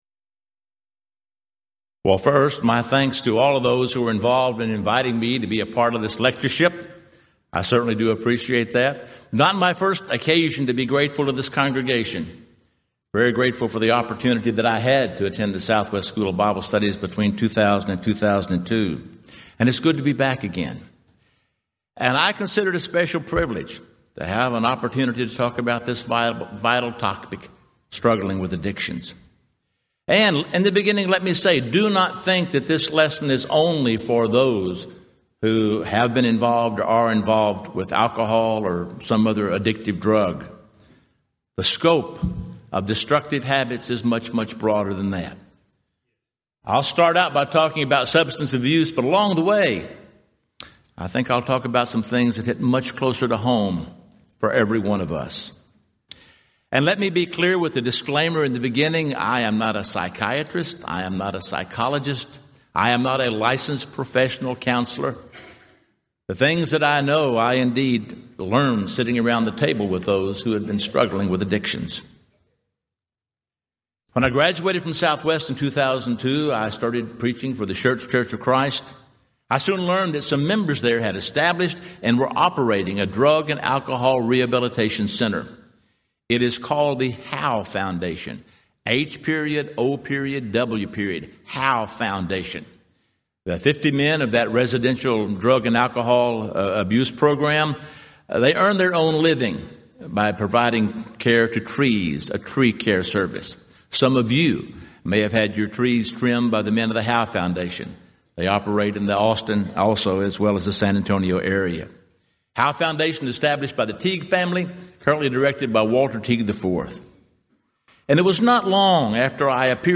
Event: 34th Annual Southwest Lectures